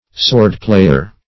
Search Result for " swordplayer" : The Collaborative International Dictionary of English v.0.48: Swordplayer \Sword"play`er\, n. A fencer; a gladiator; one who exhibits his skill in the use of the sword.